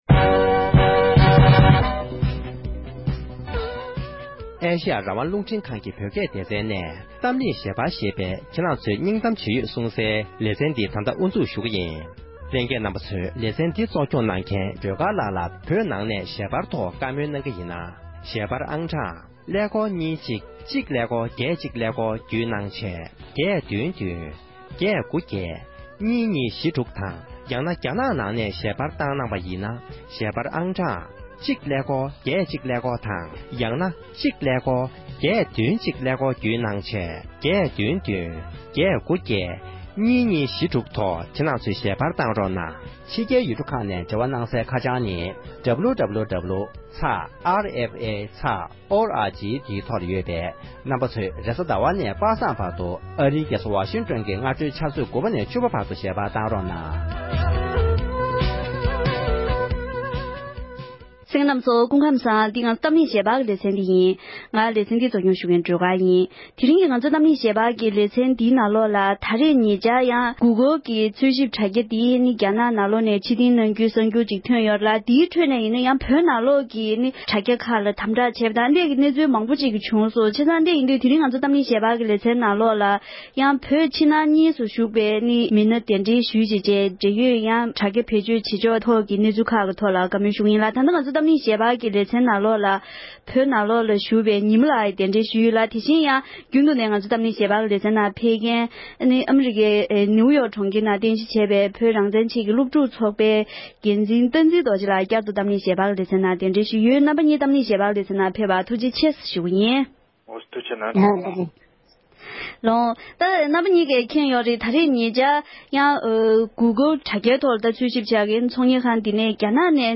གཏམ་གླེང་ཞལ་པར་